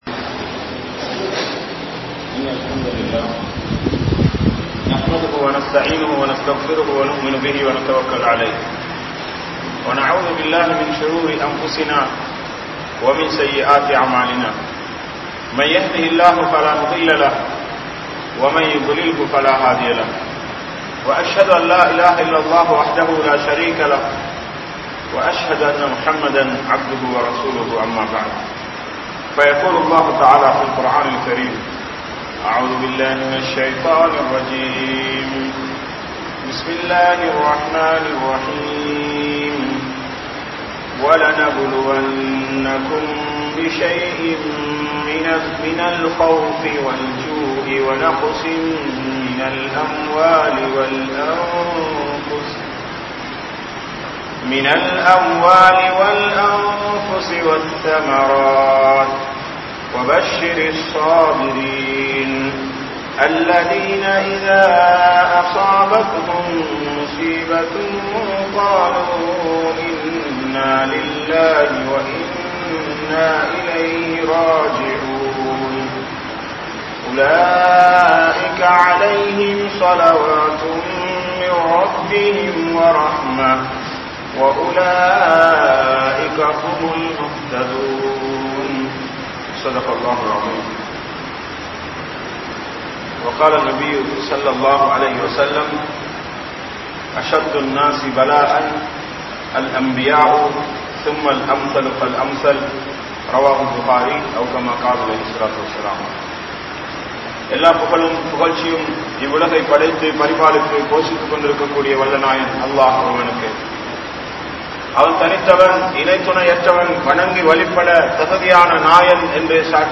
Ulaha Vaalkai Soathanaiyaanthu (உலக வாழ்க்கை சோதனையானது) | Audio Bayans | All Ceylon Muslim Youth Community | Addalaichenai